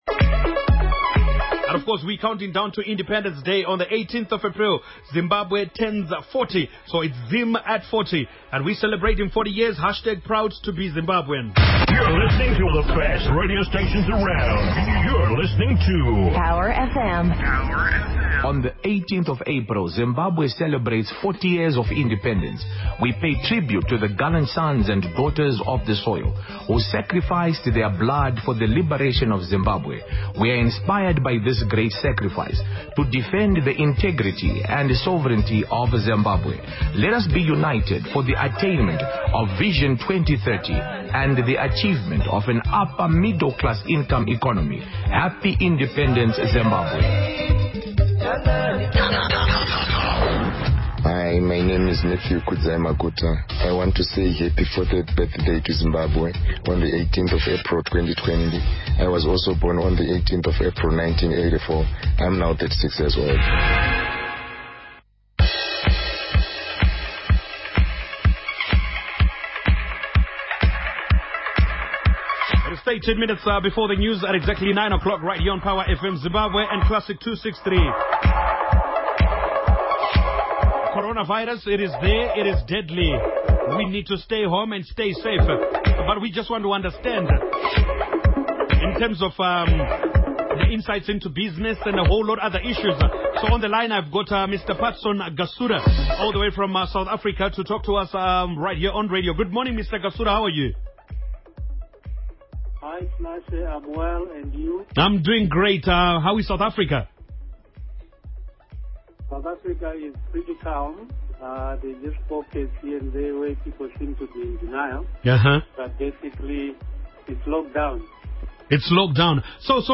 Power FM Radio Interview